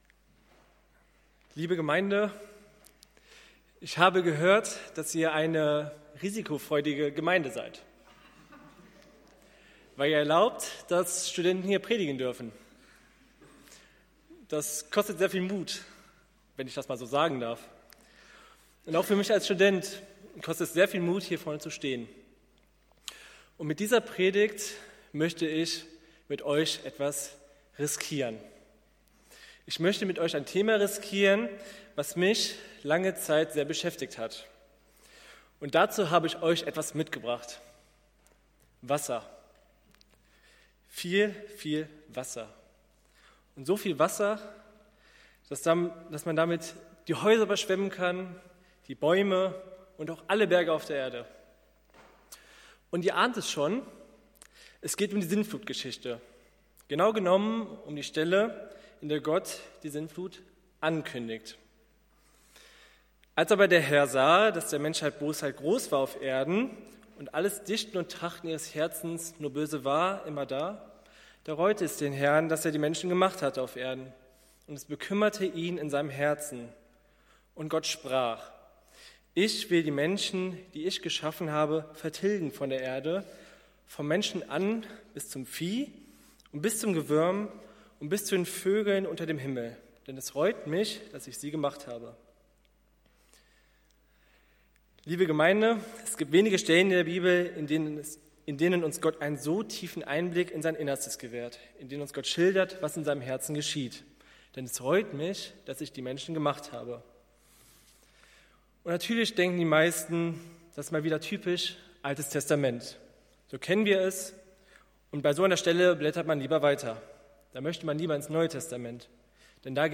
Gottesdienst
Dez. 2, 2025 | Predigten | 0 Kommentare